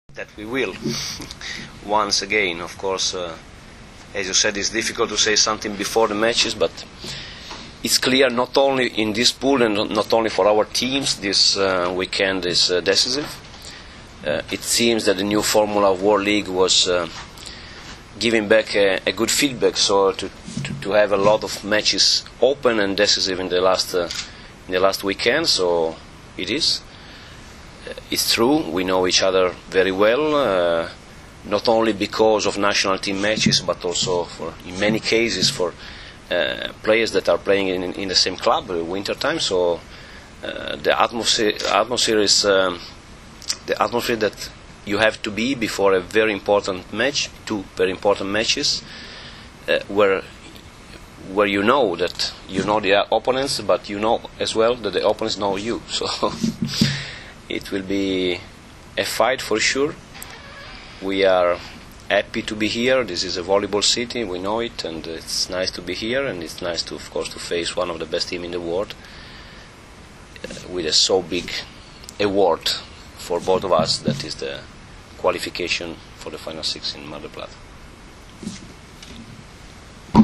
U hotelu Park u Novom Sadu danas je održana konferencija za novinare uoči utakmica poslednjeg, V V vikenda B grupe XXIV Svetske lige 2013. između Srbije i Italije.
IZJAVA MAURA BERUTA